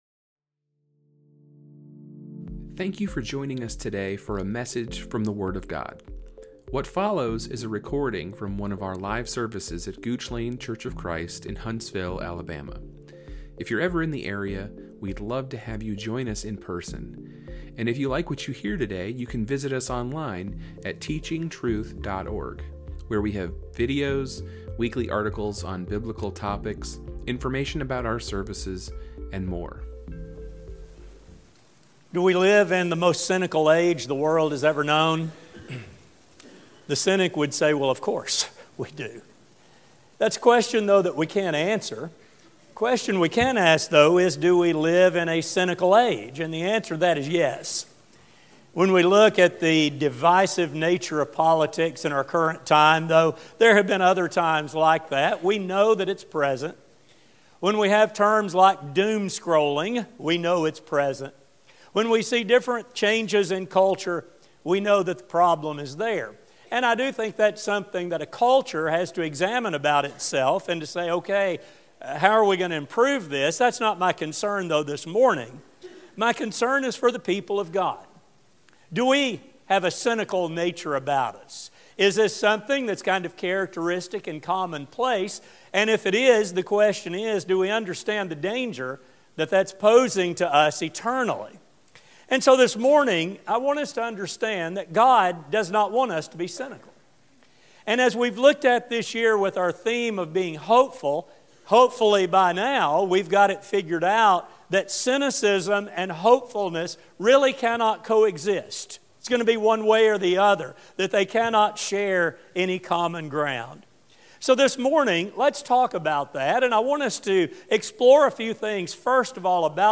This, however, is not the attitude God desires in His people because such a spirit defeats the hope with which He wants us to live. This sermon will explore the dangers posed to the cynic and provide biblical suggestions for opting instead for a spirit of hope.